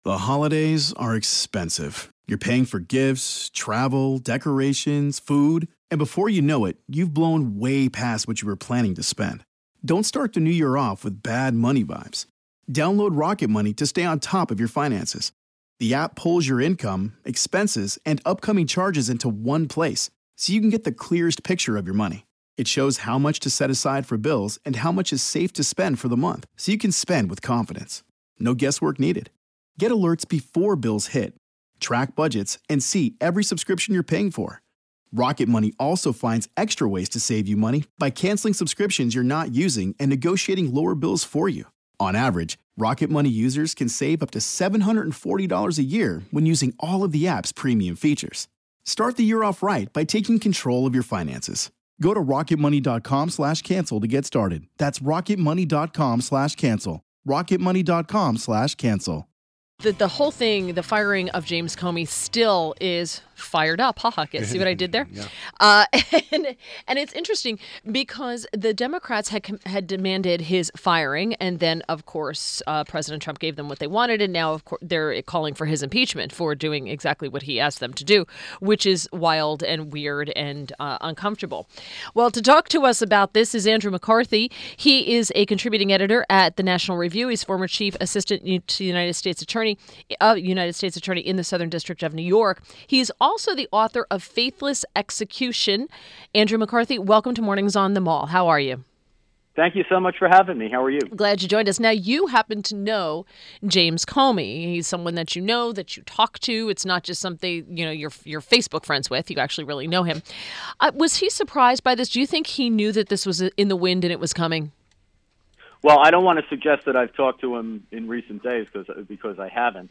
WMAL Interview - ANDREW MCCARTHY - 05.11.17